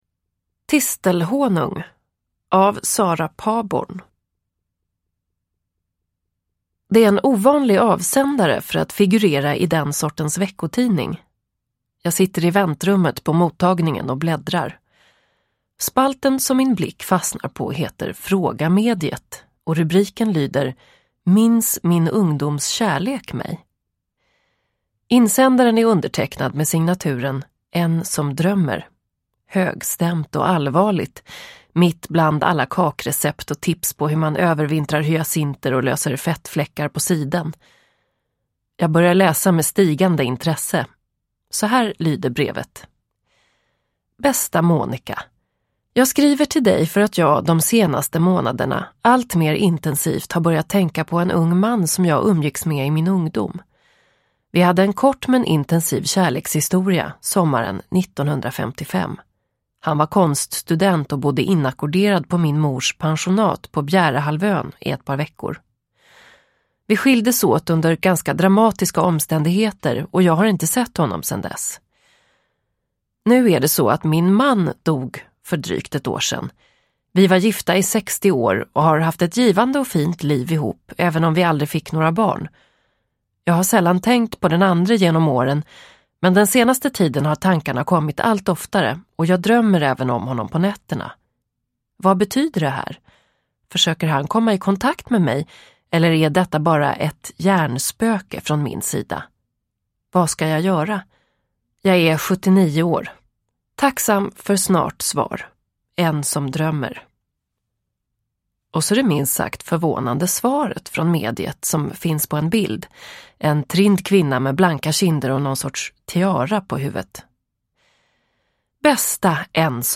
Tistelhonung – Ljudbok – Laddas ner